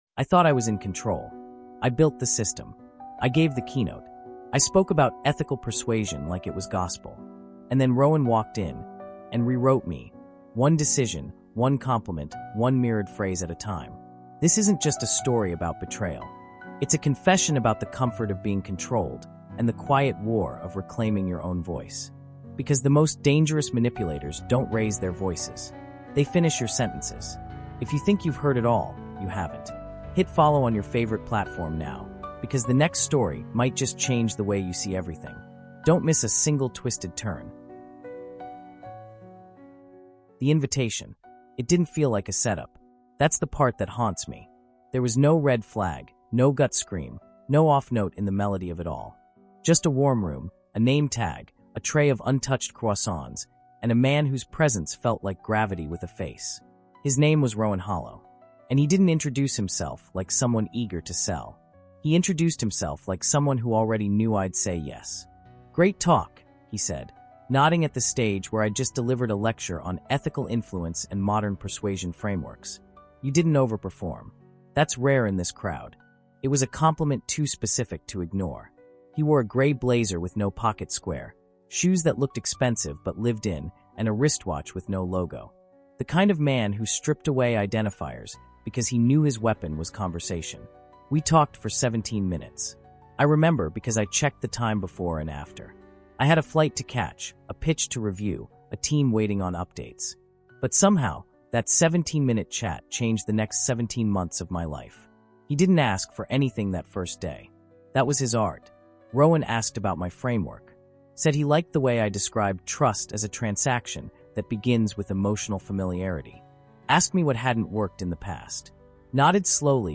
MANIPULATION: Why We Trust Manipulators and How to Stop is a gripping, emotionally immersive first-person audio thriller exploring the shadowy side of human behavior, psychological control, and identity erosion. Told through the raw lens of a tech founder blindsided by his own blind spots, this story dives deep into the psychology of manipulation, emotional validation, and cognitive dissonance.